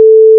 **🔊 SFX PLACEHOLDERS (23 WAV - 1.5MB):**
**⚠  NOTE:** Music/SFX are PLACEHOLDERS (simple tones)
shield_block.wav